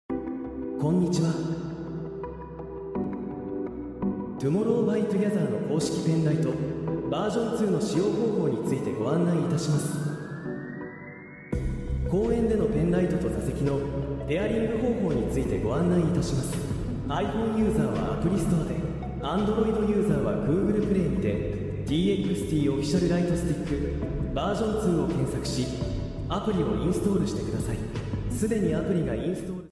※コンサートの雰囲気を感じられる様に音源は立体音響風です！